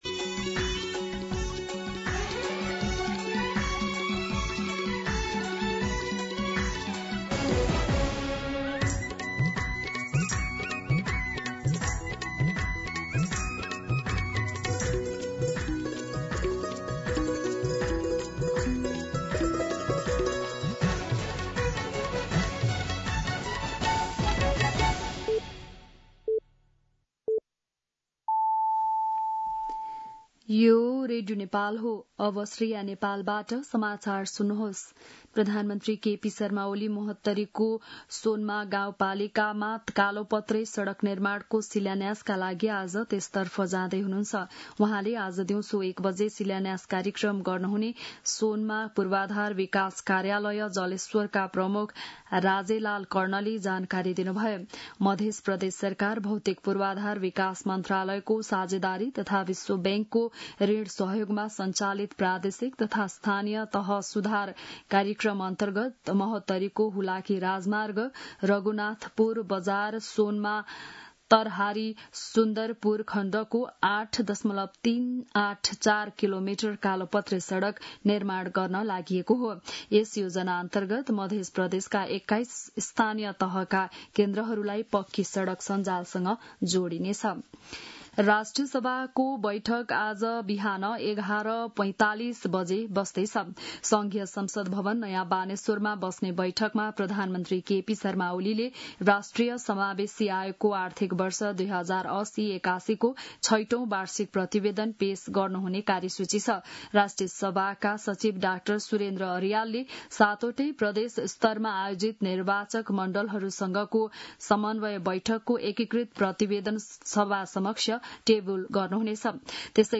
बिहान ११ बजेको नेपाली समाचार : ३० साउन , २०८२
11-am-Nepali-News-5.mp3